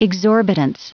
Prononciation du mot exorbitance en anglais (fichier audio)